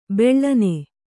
♪ beḷḷane